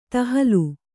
♪ tahalu